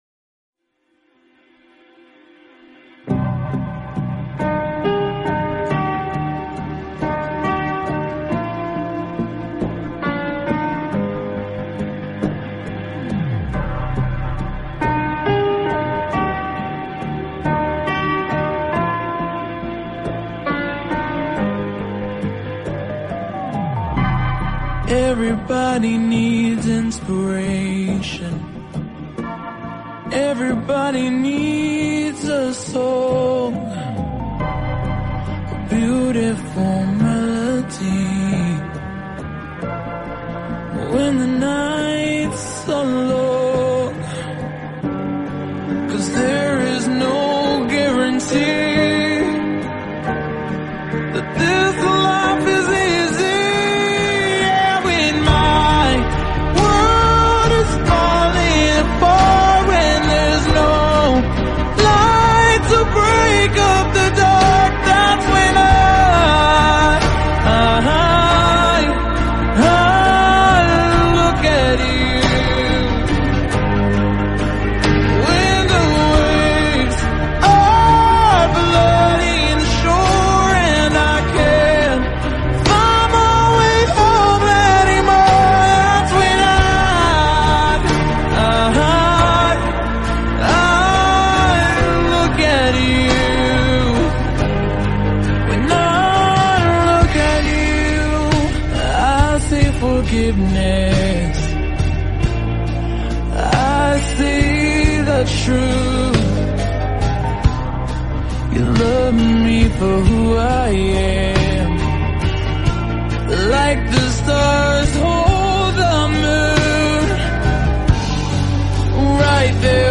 male version